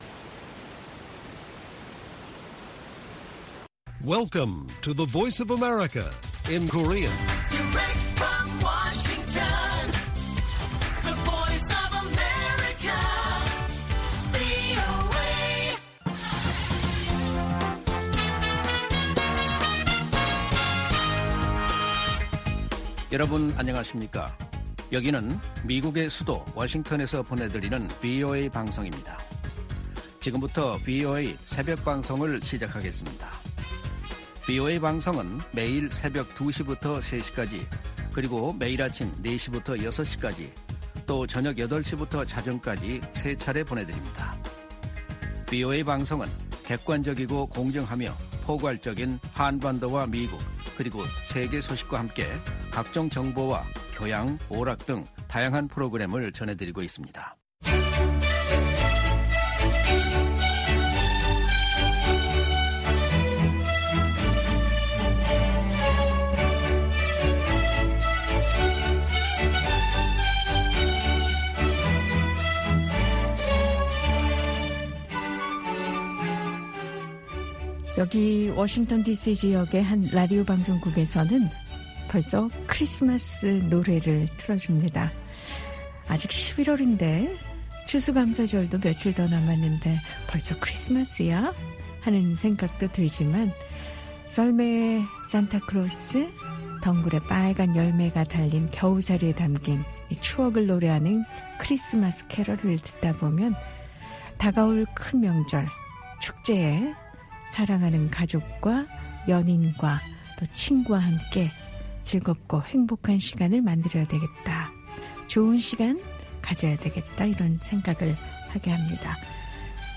VOA 한국어 방송의 일요일 새벽 방송입니다.